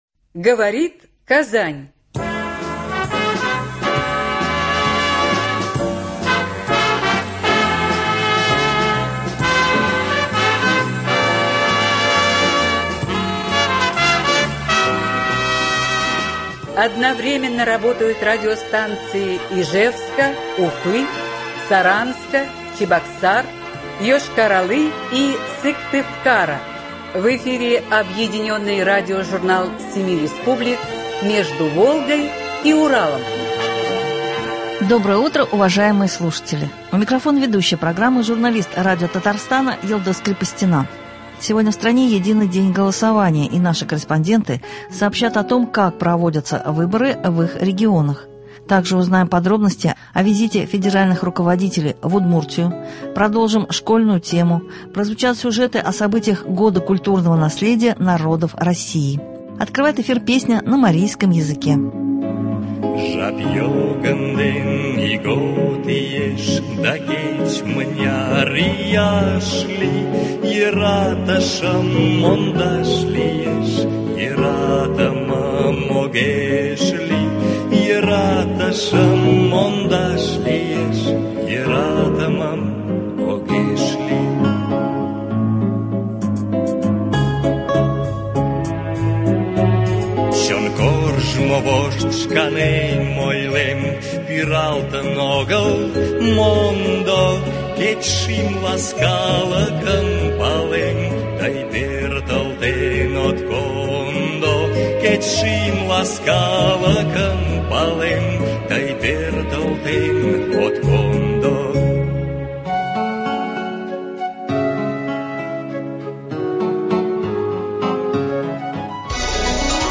Объединенный радиожурнал семи республик.
Сегодня в стране Единый день голосования, и наши корреспонденты сообщат о том, как проводятся выборы в их регионе.